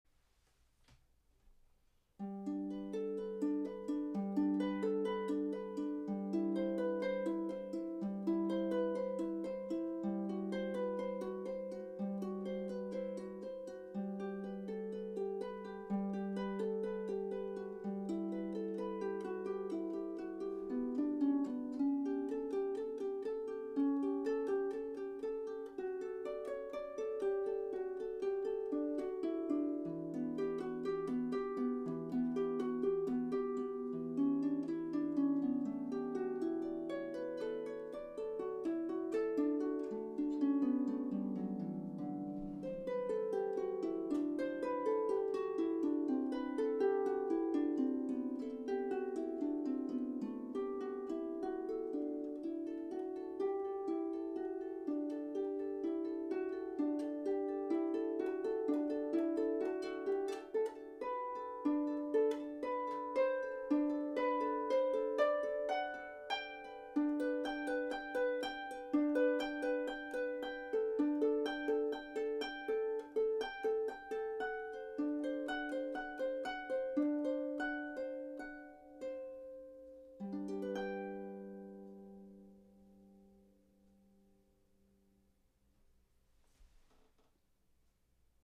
for solo lever or pedal harp. This simplified version
This piece includes an optional ending using harmonics.